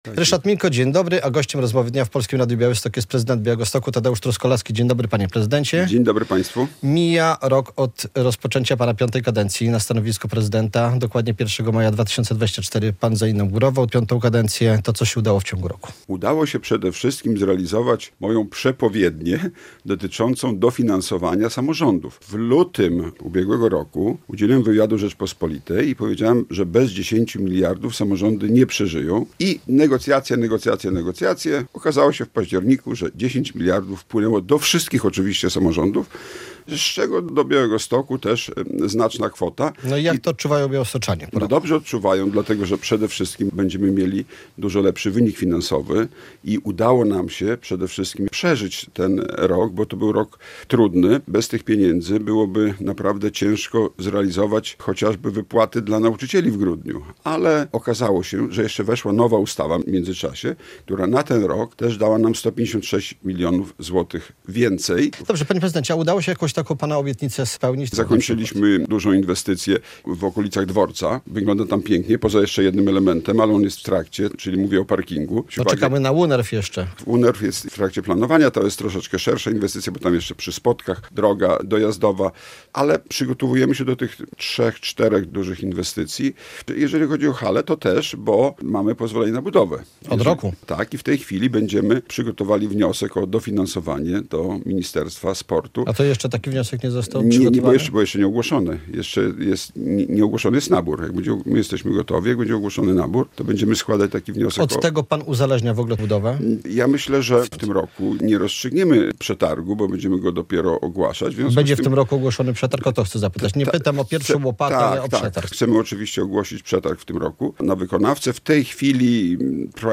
To tematy, jakie poruszyliśmy w Rozmowie Dnia z prezydentem Białegostoku Tadeuszem Truskolaskim.